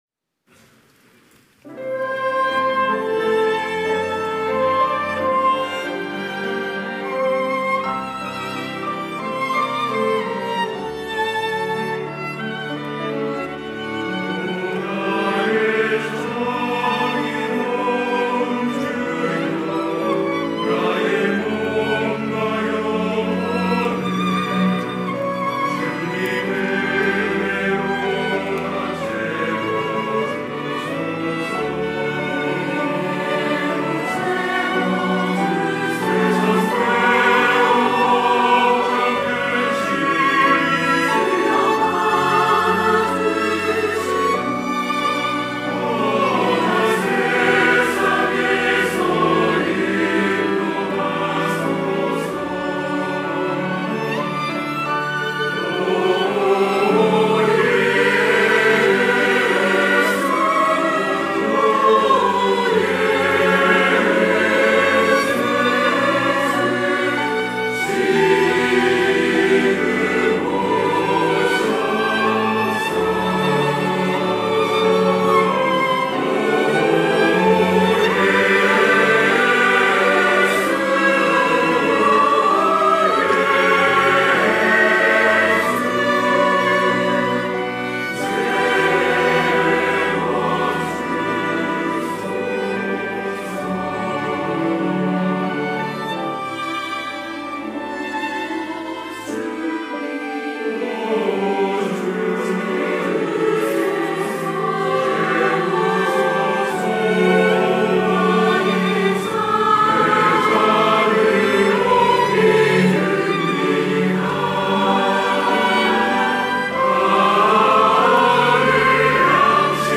찬양대 호산나